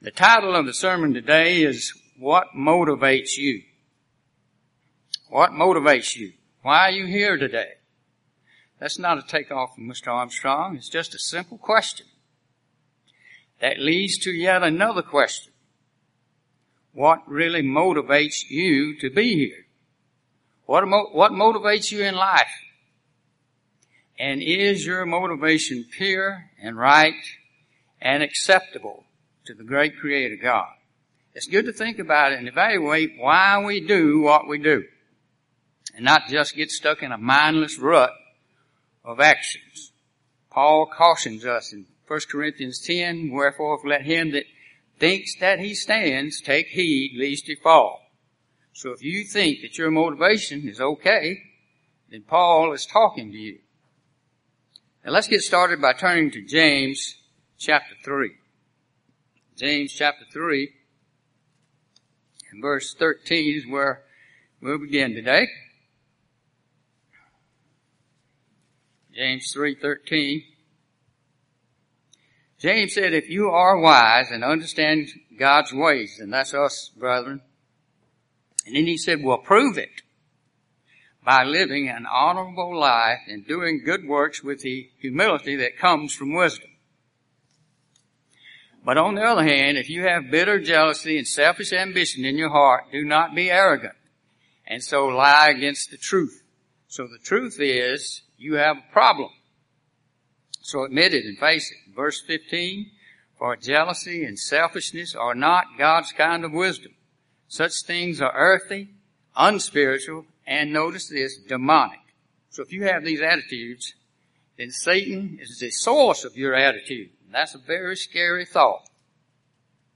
UCG Sermon Studying the bible?
Given in Columbus, GA Central Georgia